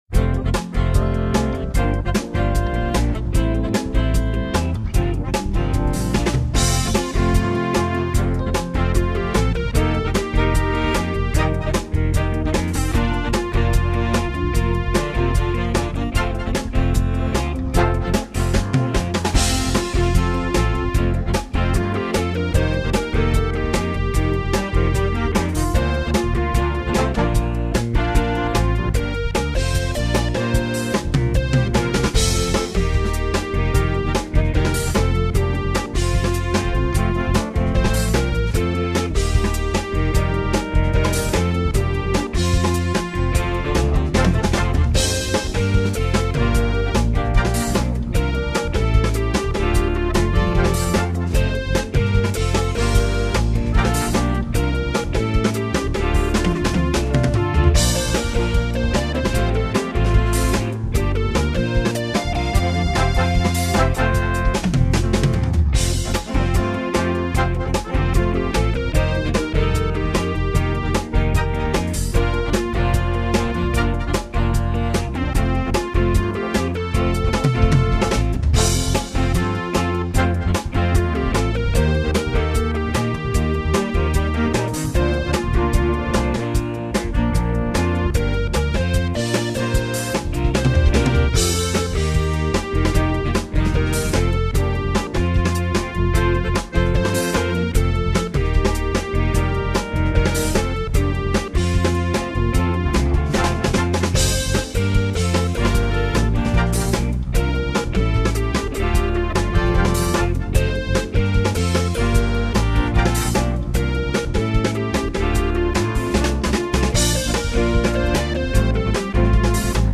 up-tempo meditation